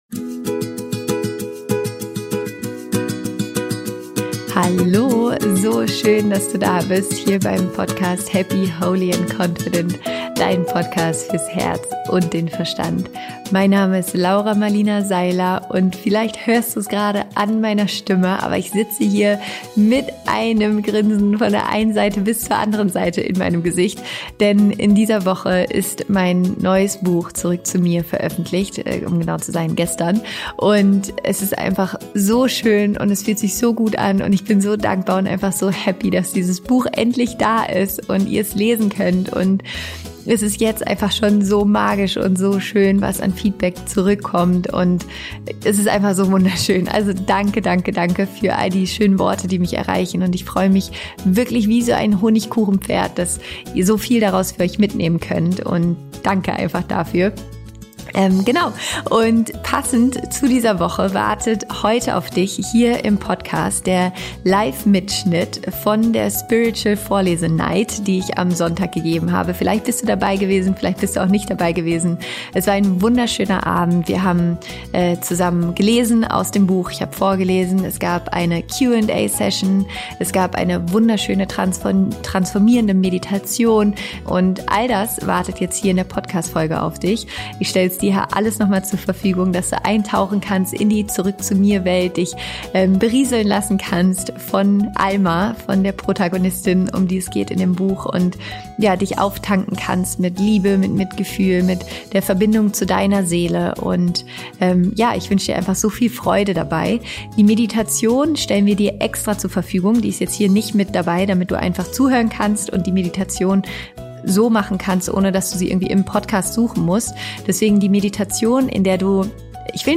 Passend zur Veröffentlichung des Buches gab es am Sonntag die wunderschöne Spiritual Night, in der ich aus dem Buch vorgelesen habe, ich ein Live-Coaching mit einer Teilnehmerin gegeben habe, wir gemeinsam meditiert haben und in der es eine Q&A Session gab.
Deshalb gibt es in dieser Podcastfolge einen Live-Mitschnitt von der Spiritual Night, sodass du mit mir gemeinsam in das Buch eintauchen, dich mit ganz viel Liebe, Mitgefühl und Dankbarkeit aufladen und dich mit deiner Seele verbinden kannst. In dieser Folge teile ich mit dir die Entstehungsgeschichte von meinem Buch.